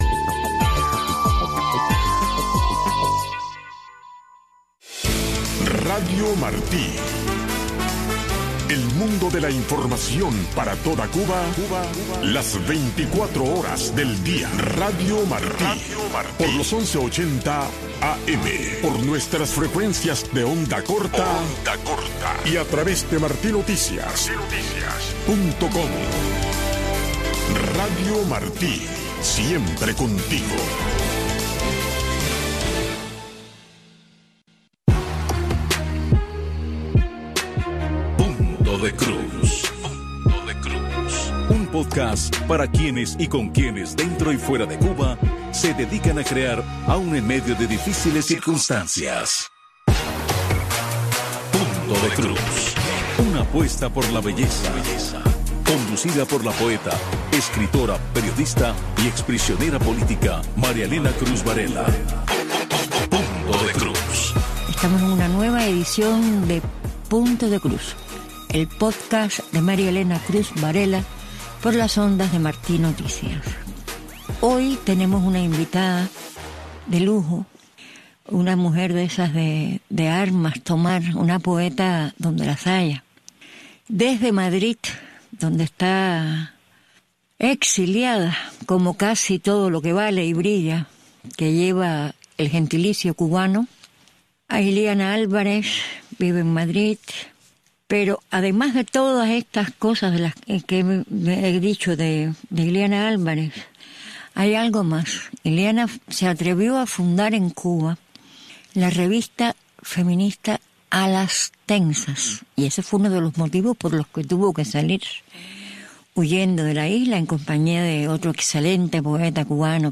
Las voces que testimonian la vida del cubano de a pie.